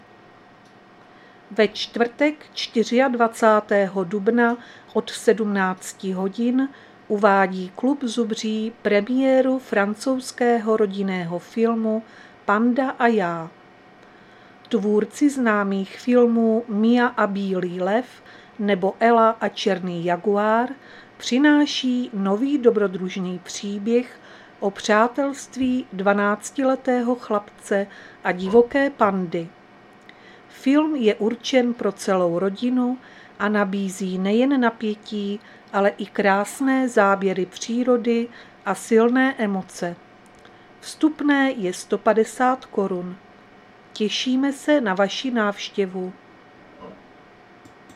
Záznam hlášení místního rozhlasu 23.4.2025
Zařazení: Rozhlas